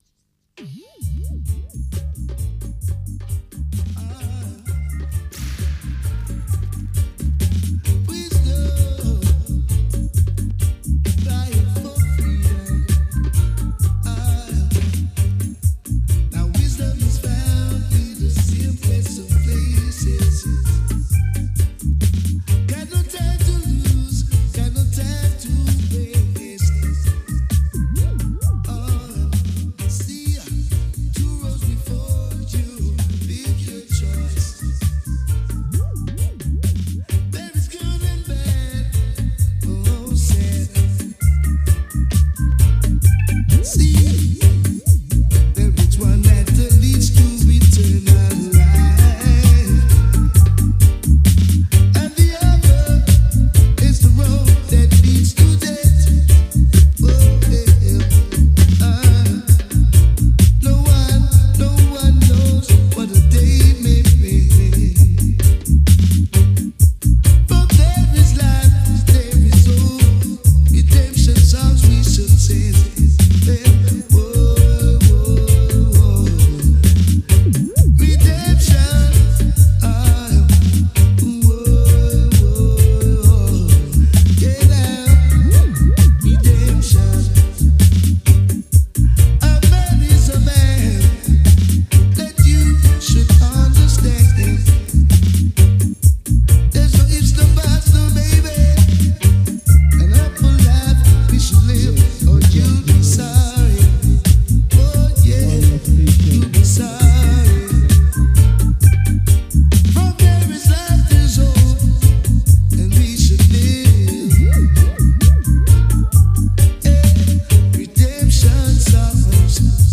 ROOTS ROCK REGGAE